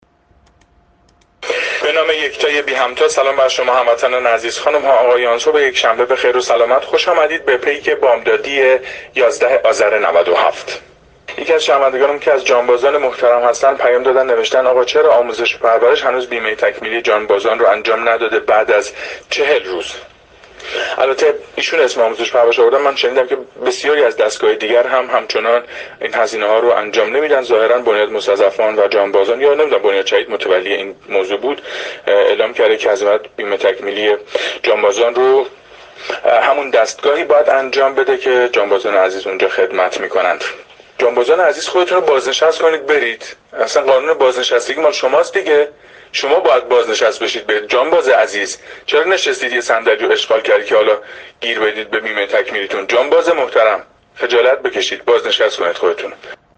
هرچند لحن گفتار این مجری خوش سابقه نیز لحن مناسبی نبود و می‌توانست از عبارتهای بهتری هم استفاده شود که گزک به دست بهانه گیران ندهد اما شاید لازم باشد افکار عمومی‌خود در این باره قضاوت کننند.